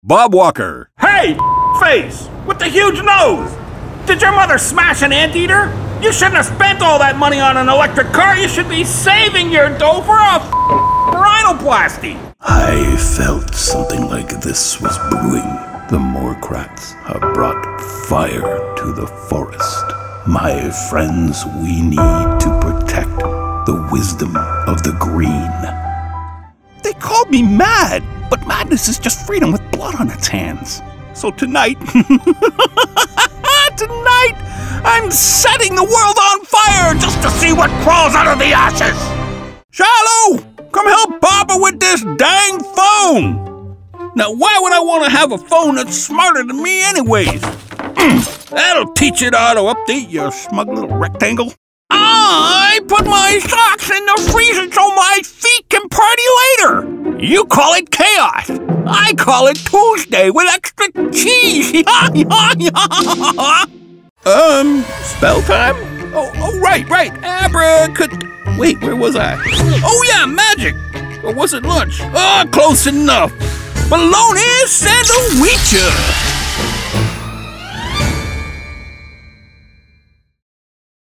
Personable, Inspiring, Well-Spoken, and Jovial Voice Ready to Bring Your Project to Life... and all from my professional grade home studio.